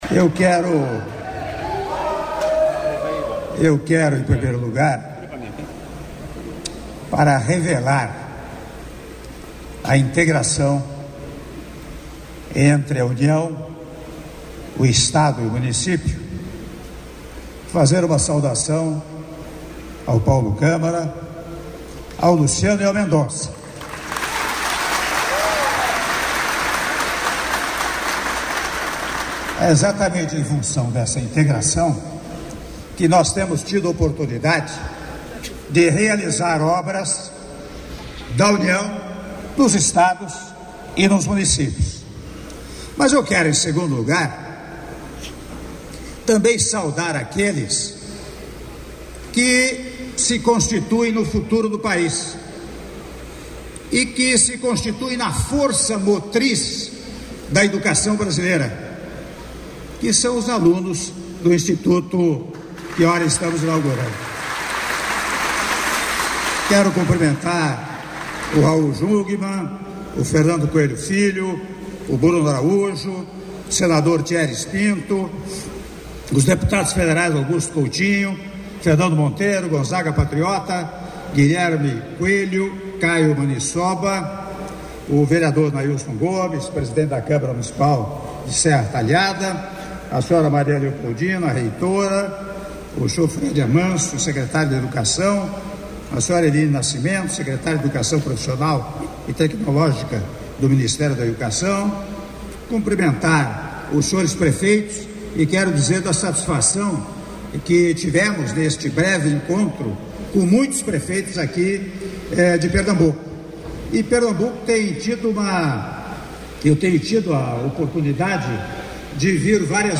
Áudio do discurso do presidente da República, Michel Temer, durante cerimônia de Inauguração do novo Campus de Serra Talhada do Instituto Federal do Sertão Pernambucano - Serra Talhada/PE (07min43s)